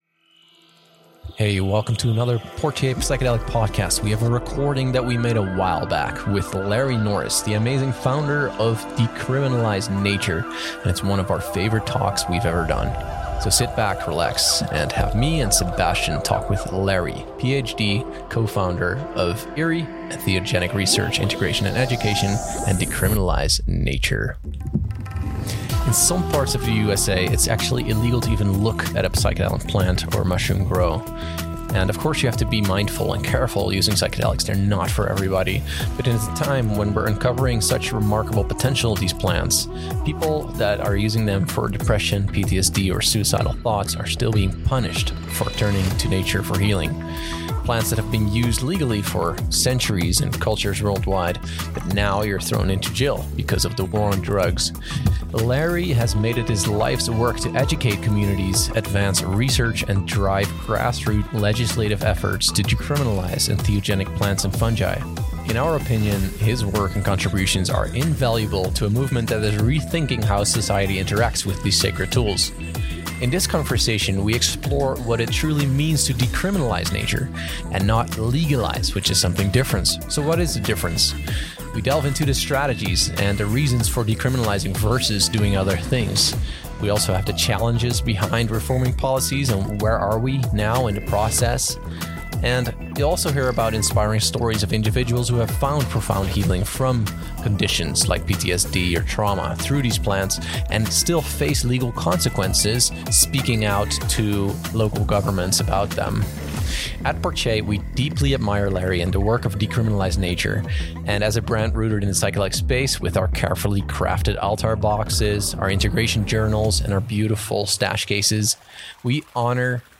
Each episode is a unique trip, featuring interviews with thought leaders, entrepreneurs, researchers, facilitators, non-profits, and philosophers.